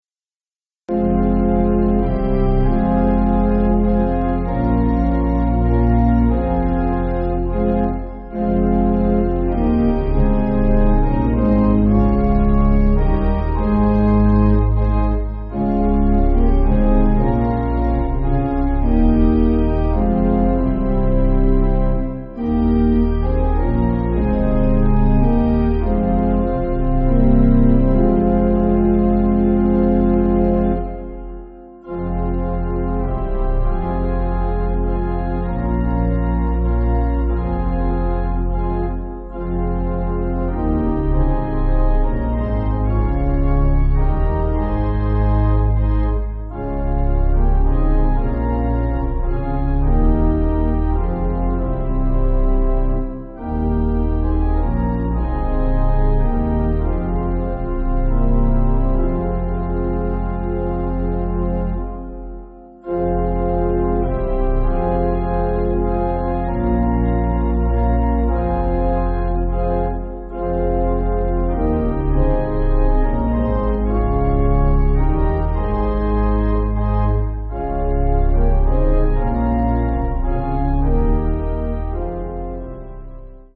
(CM)   4/Fm